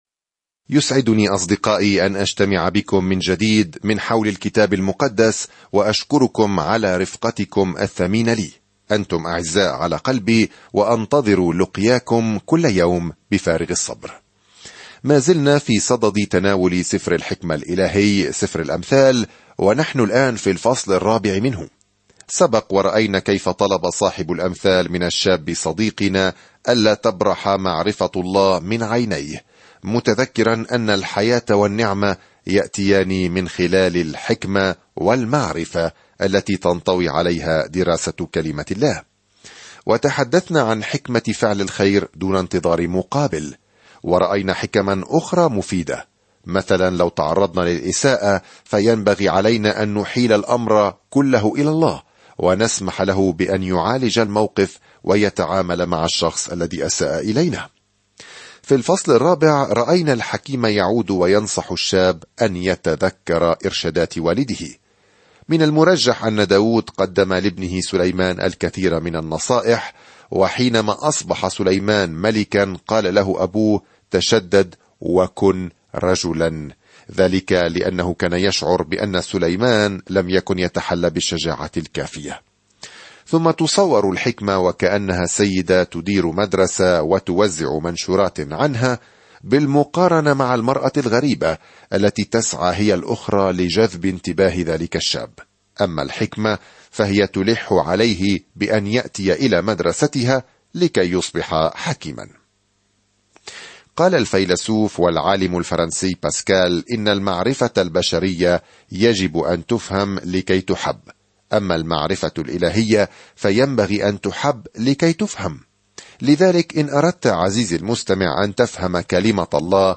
الكلمة أَمْثَالٌ 10:4-27 أَمْثَالٌ 1:5-14 يوم 6 ابدأ هذه الخطة يوم 8 عن هذه الخطة الأمثال هي جمل قصيرة مستمدة من تجارب طويلة تعلم الحقيقة بطريقة يسهل تذكرها - حقائق تساعدنا على اتخاذ قرارات حكيمة. سافر يوميًا عبر الأمثال وأنت تستمع إلى الدراسة الصوتية وتقرأ آيات مختارة من كلمة الله.